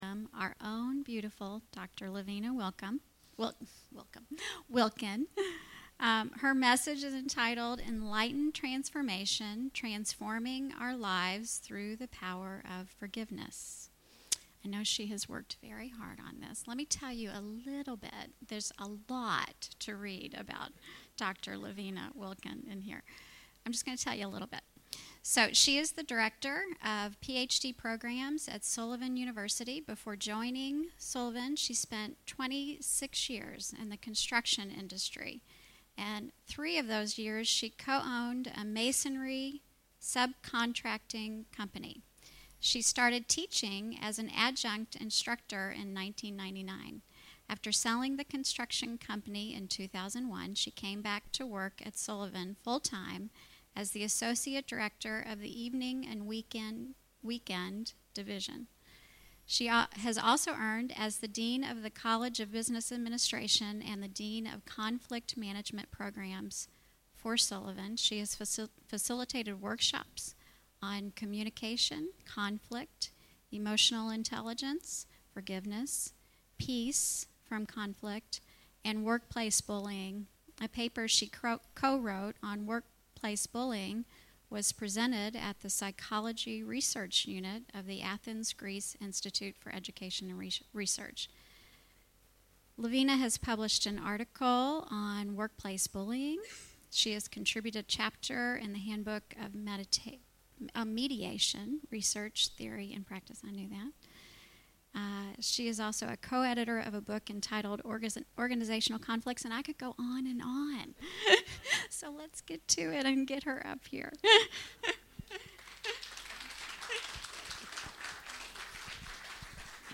Her Talk on Forgiveness